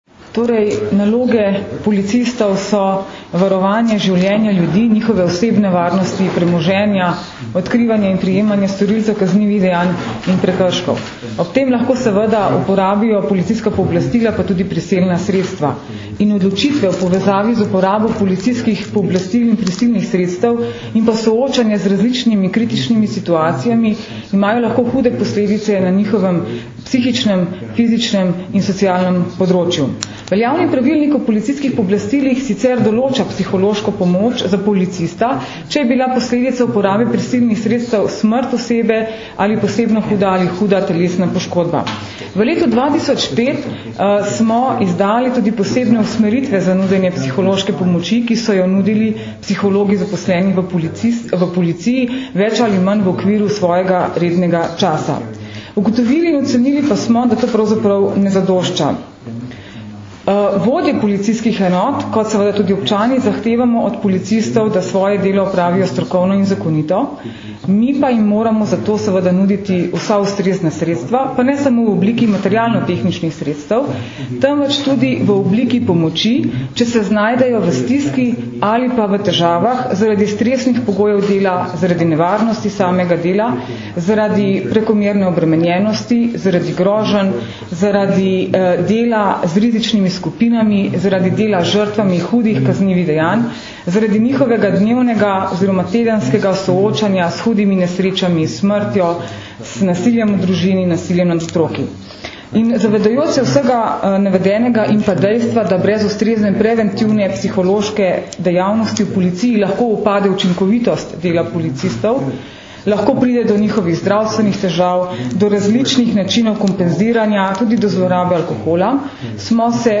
Policija - Psihološka pomoč in zaščita policistov - informacija z novinarske konference
Zvočni posnetek izjave mag. Tatjane Bobnar (mp3)